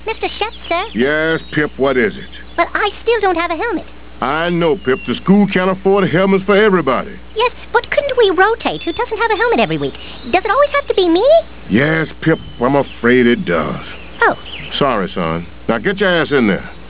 - Chef telling Pip he can't have a helmet. 133kb